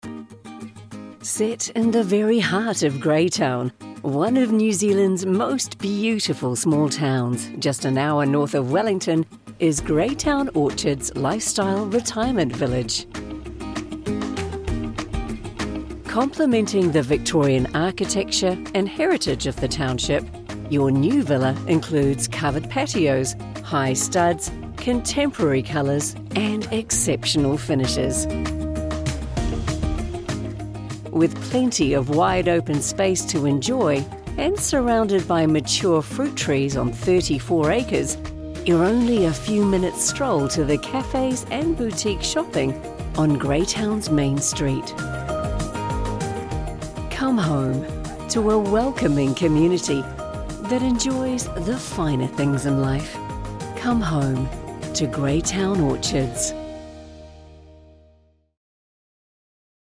I have my own home studio and can deliver quickly.
My voice is described as warm, friendly, and authentic, perfect for a multitude of different genres I am confident in French, and German, offering versatility for international projects.